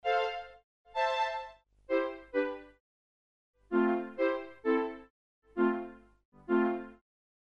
描述：4条弦乐循环。两把小提琴和一把大提琴
Tag: 130 bpm Orchestral Loops Strings Loops 1.24 MB wav Key : Unknown